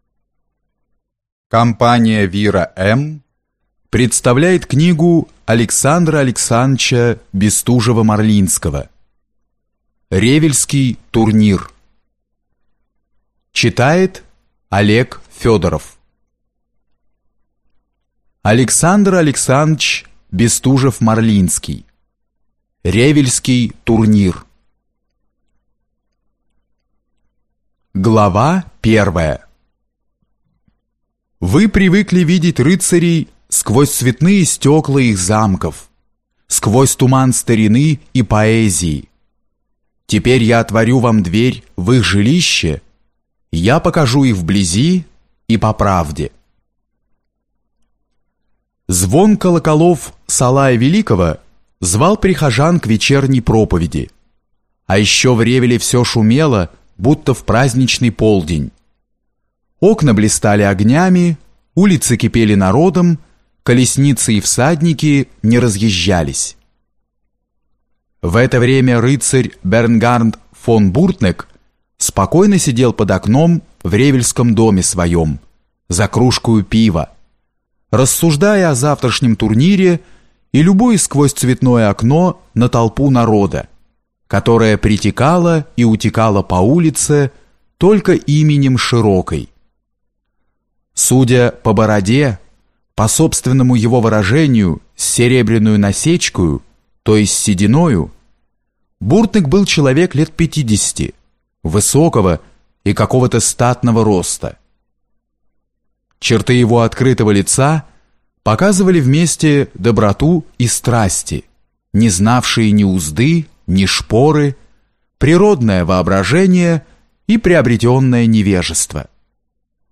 Аудиокнига Ревельский турнир | Библиотека аудиокниг